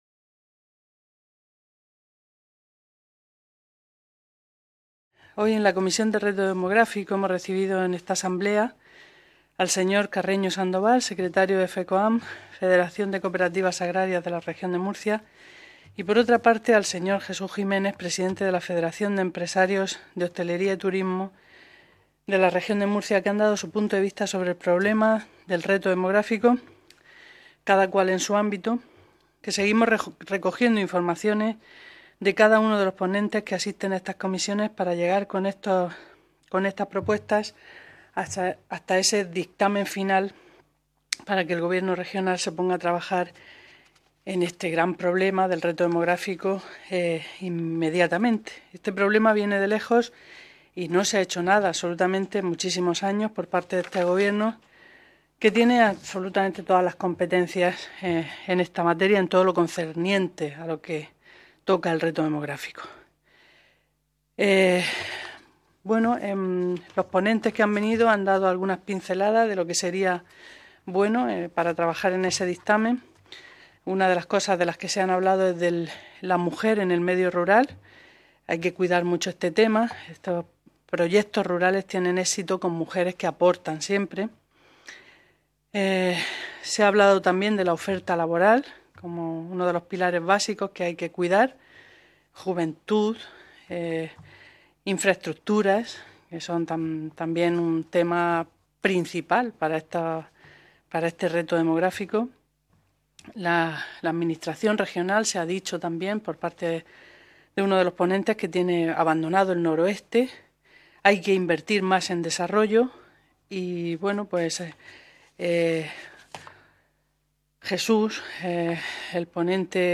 Ruedas de prensa tras la Comisión Especial de Estudio para abordar el Reto Demográfico y la Despoblación en la Región de Murcia
• Grupo Parlamentario Socialista